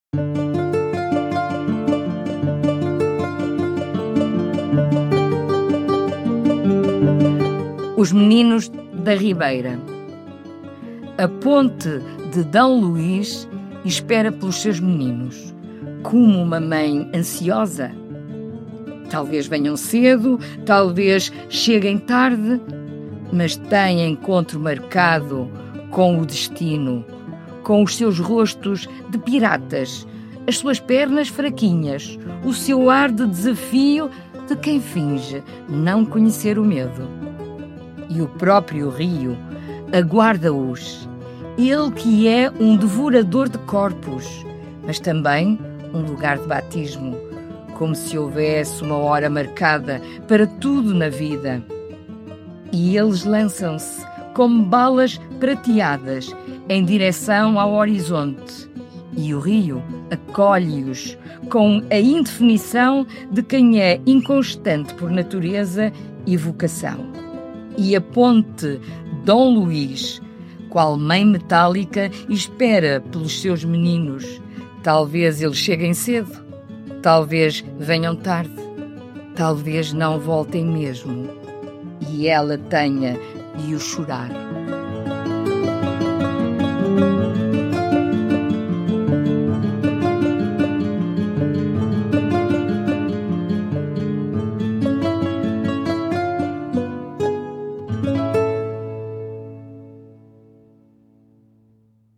Música: Baroque Lute Classical Music Background, por EpicMann, Licença Envato – Free Files Single Use Policy.